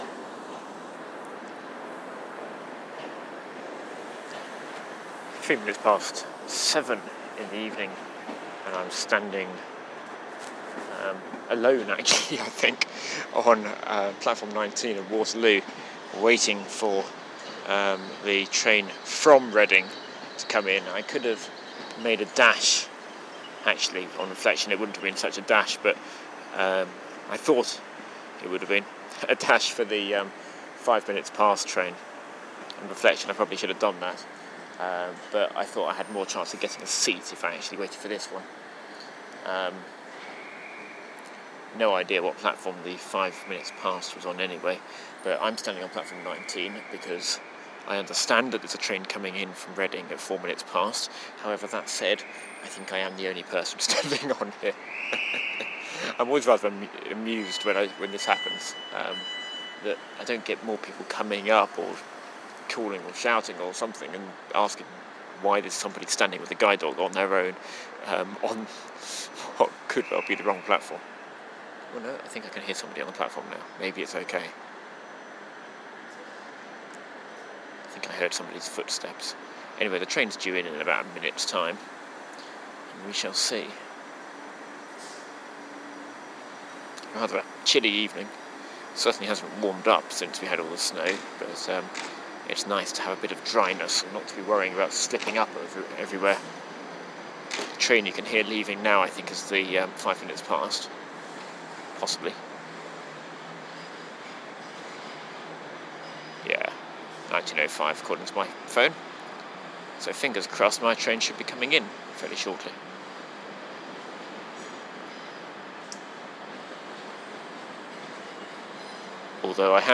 Listening to the train filling up